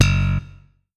slapbass.wav